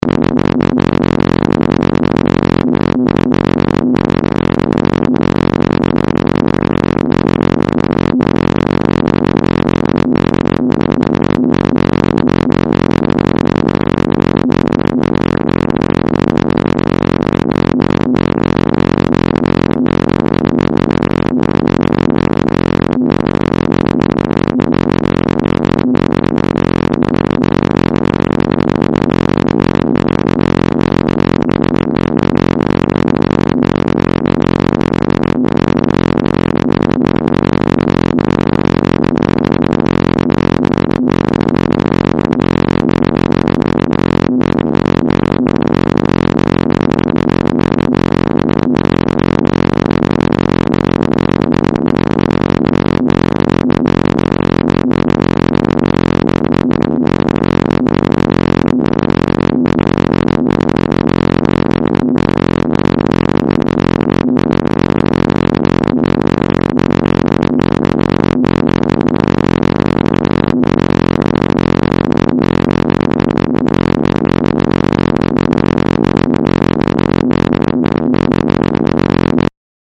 Subtle small pieces of noise. Made only with No Input Mixer in 2004.